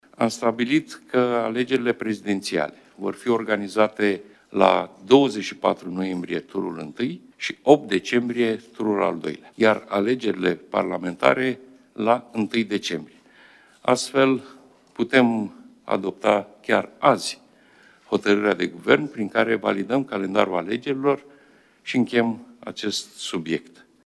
Premierul Marcel Ciolacu, în deschiderea ședinței de Guvern.